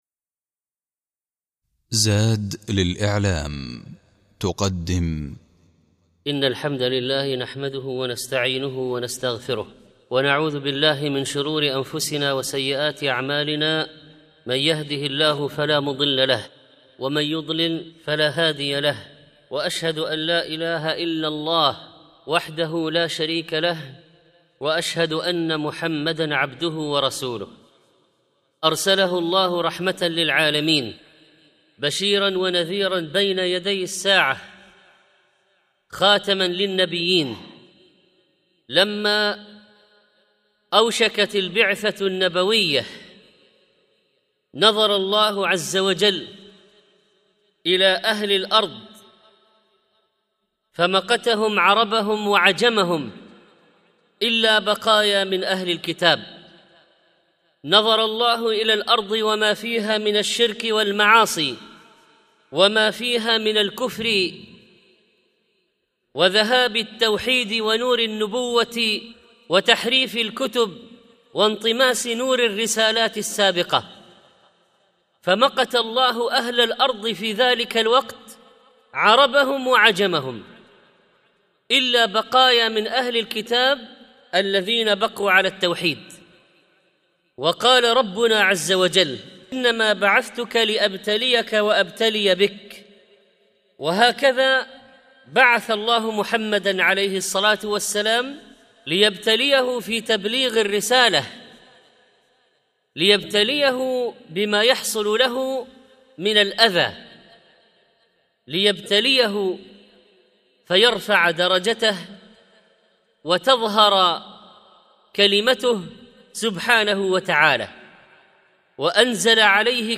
الخطبة الأولى
الخطبة الثانية